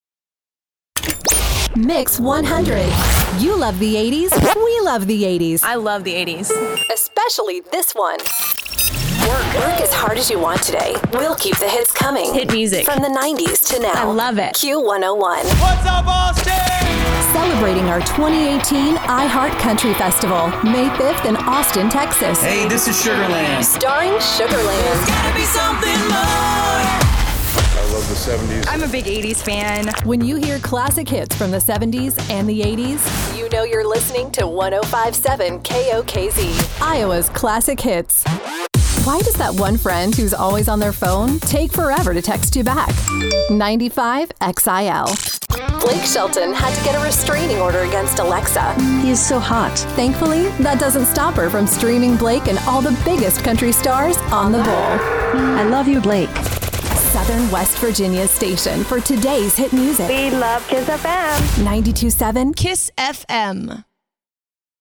Radio Imaging – Hot AC
Radio-Imaging-Hot-AC.mp3